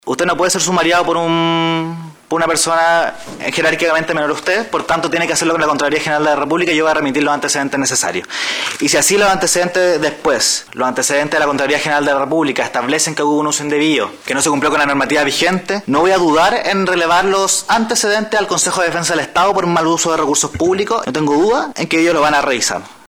En una sesión extraordinaria del Concejo Municipal de Puchuncaví, región de Valparaíso, se abordaron las diversas irregularidades que rodean el accidente de tránsito protagonizado el pasado fin de semana por el alcalde de la comuna, Marcos Morales, quien a bordo de un vehículo municipal colisionó con otro automóvil en Quintero.
Respecto a la investigación administrativa, Benjamín Angulo, edil del Frente Amplio, recalcó que presentará un requerimiento en la Contraloría General de la República para asegurar una indagatoria que no se vea truncada por la posición jerárquica del alcalde.